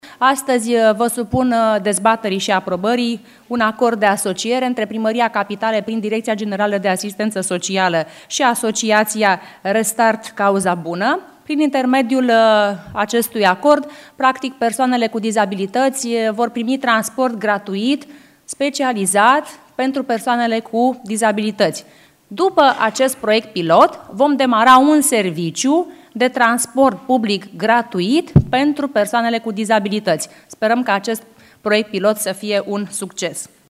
AUDIO: Gabriela Firea, Primarul General al Capitalei, descrie serviciul de taxi pentru persoanele cu disabilitati >
Gabriela-Firea-descrie-serviciul-de-taxi-pentru-persoanele-cu-disabilitati.mp3